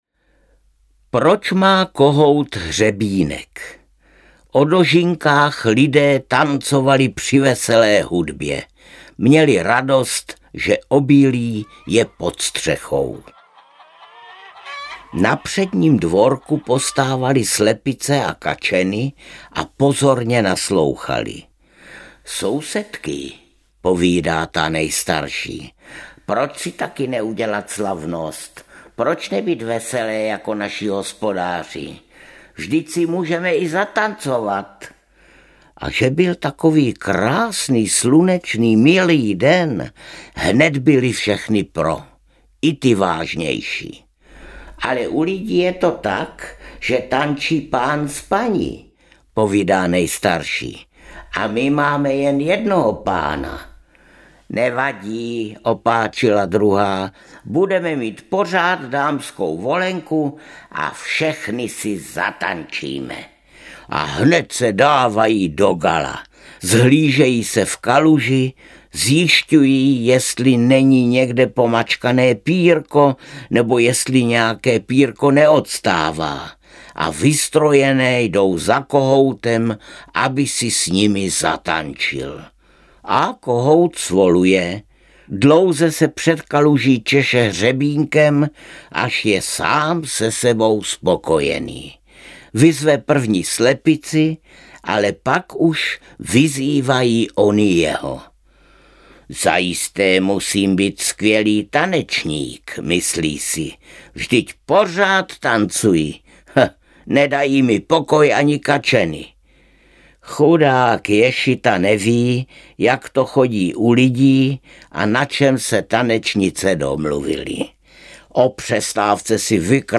Ukázka z knihy
Čte Arnošt Goldflam. Nahráno v červnu 2017 v Recording Studiu Škroupova Brno.